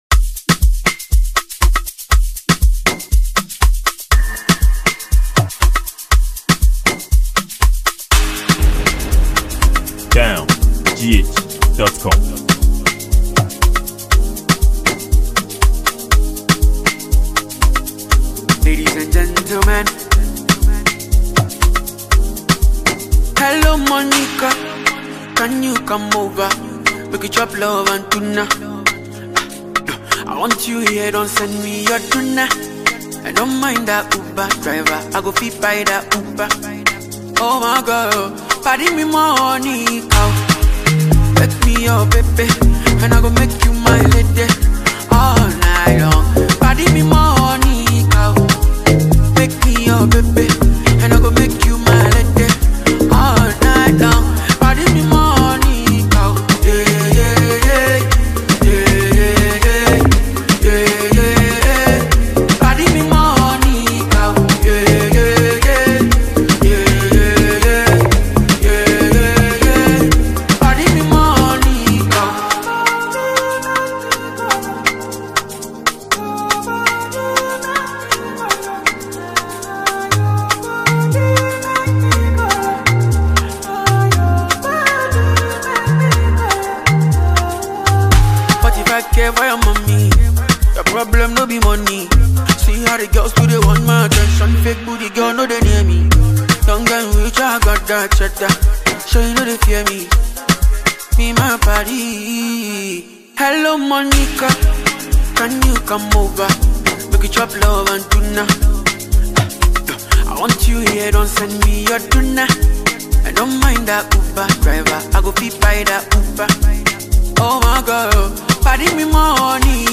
Ghana’s highlife musician and songwriter